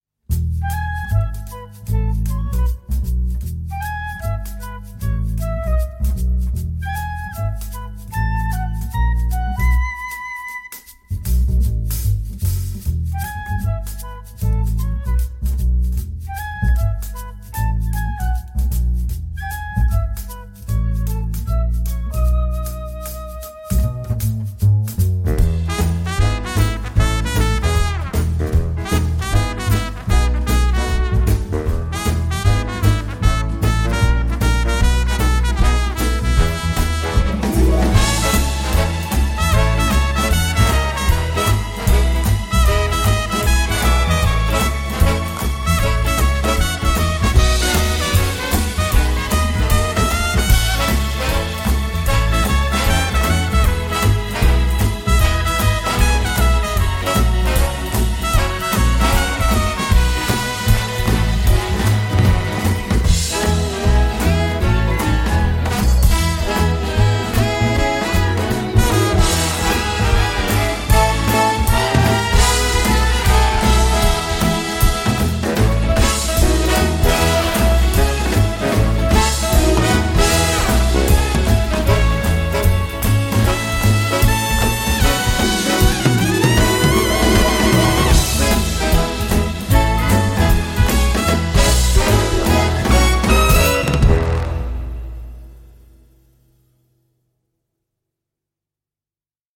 Une excellente surprise !